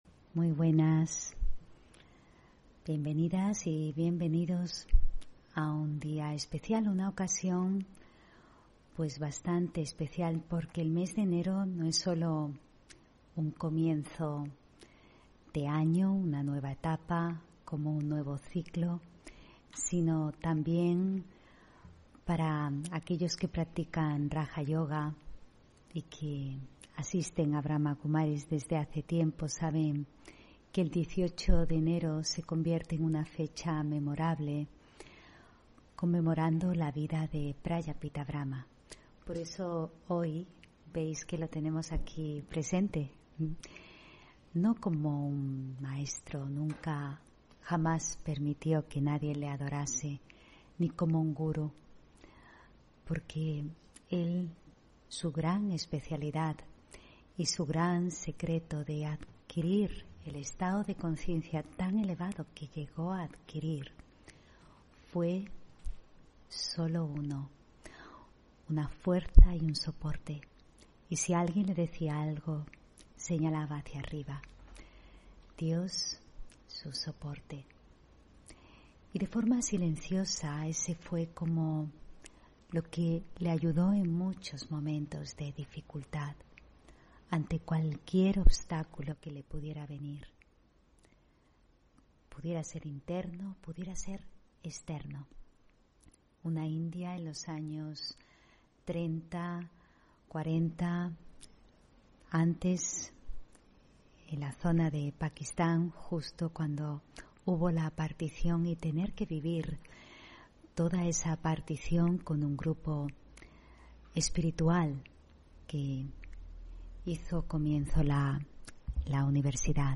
Meditación y conferencia: Una mente libre y sin fronteras (19 Enero 2022)